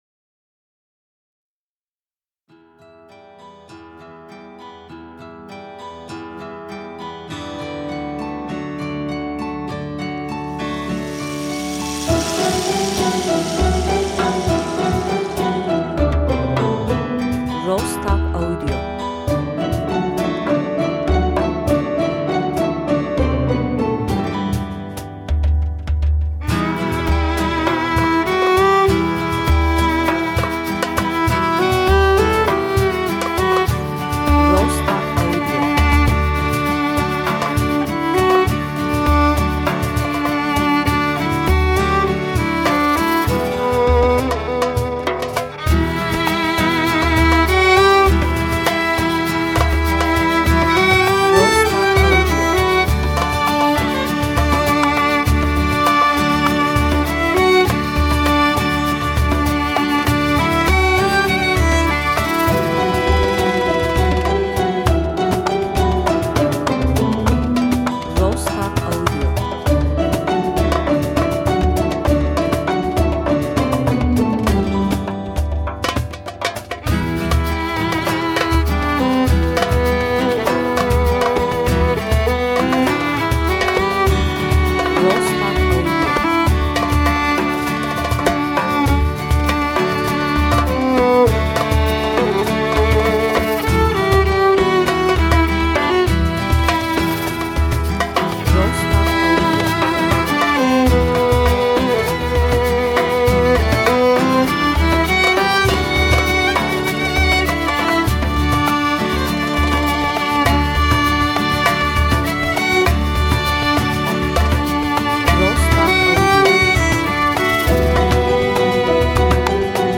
enstrümantal